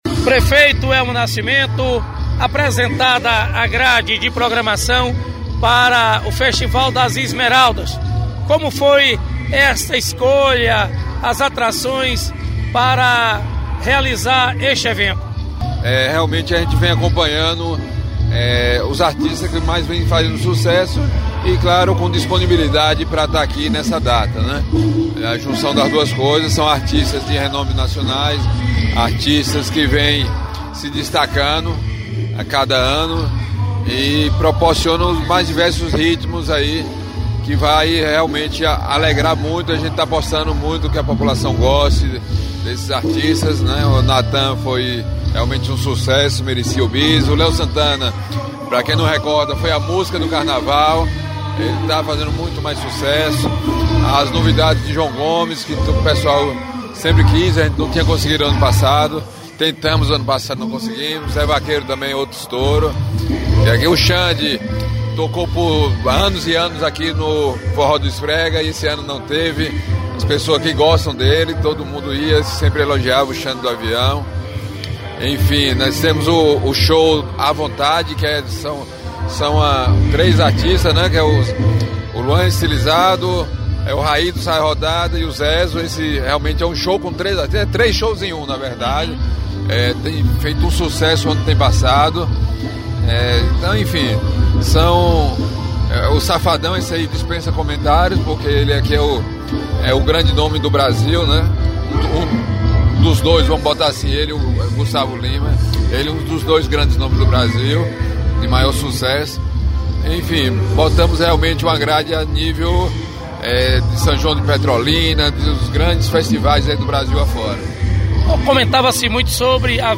Prefeito Elmo Nascimento apresenta as atrações para o Festival das Esmeraldas este ano